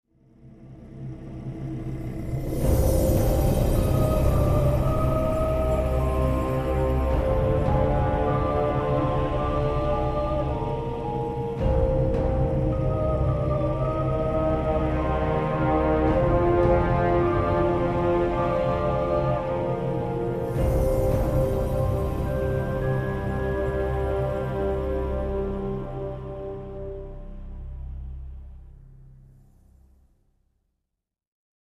Зы, фоновые звуки - что то вроде этого..